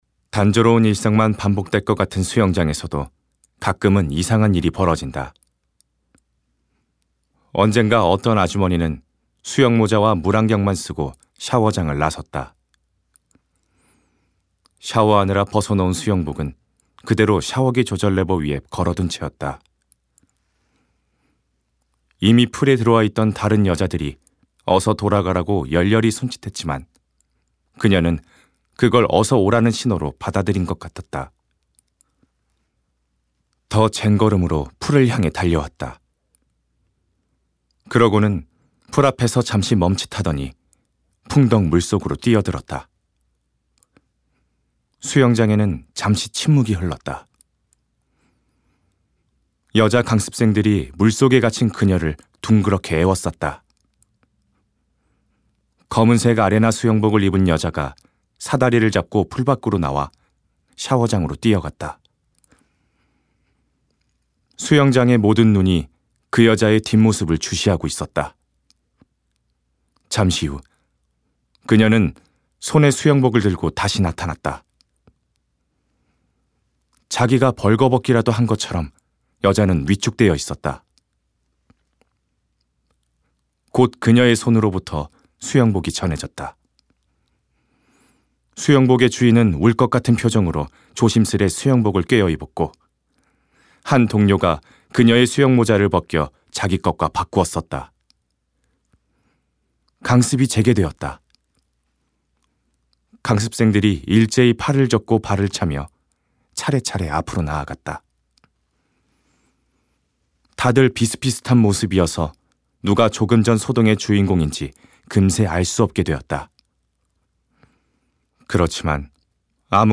깔끔한 목소리(성우,나레이션)로 작업해드립니다
차분하고 진정성있는 목소리입니다.
홍보와 나레이션 위주로 녹음했습니다.